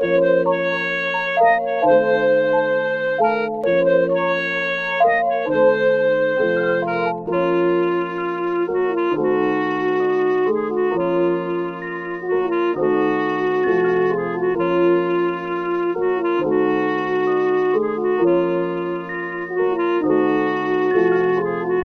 godfather_wya_66bpm_oz.wav